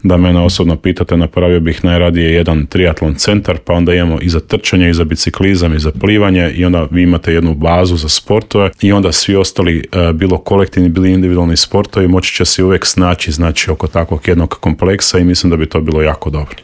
Kakva je ponuda i što sve čeka posjetitelje u Intervjuu Media servisa otkrio nam je tamošnji gradonačelnik Dario Zurovec.